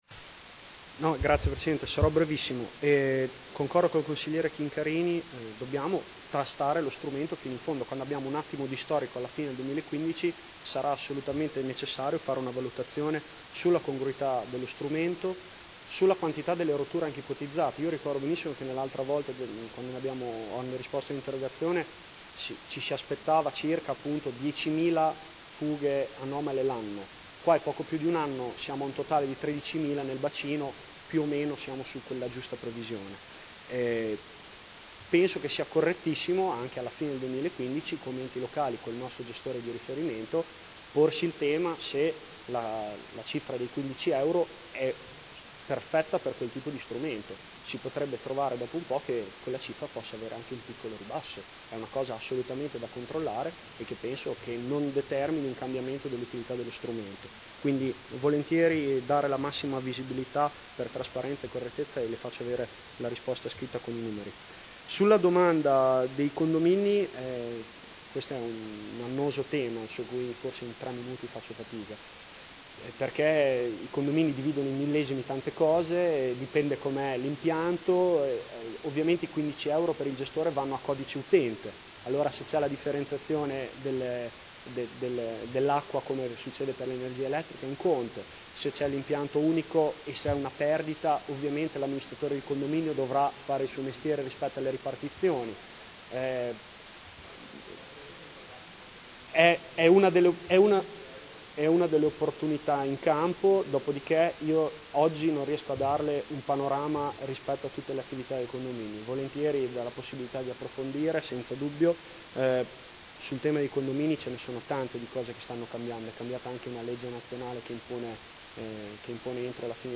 Giulio Guerzoni — Sito Audio Consiglio Comunale